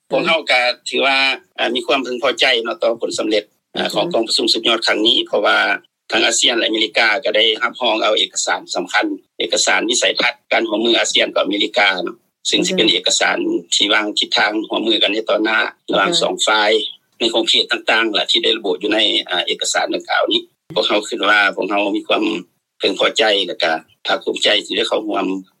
ສຽງ 1 ທ່ານທອງຜ່ານ ສະຫວັນເພັດກ່າວກ່ຽວກັບກອງປະຊຸມ